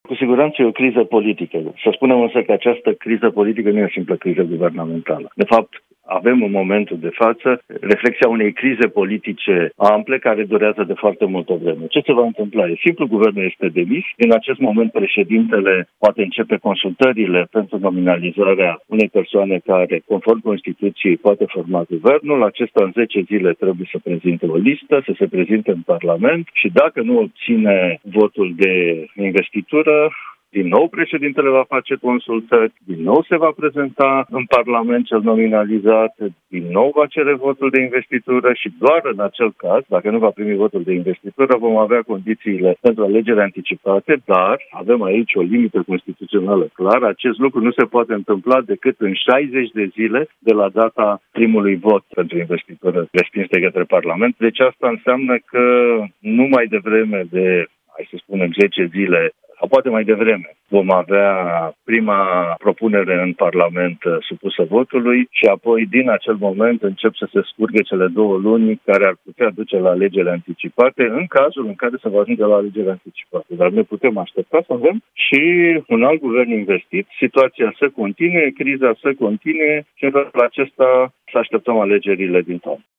într-un interviu pentru Europa FM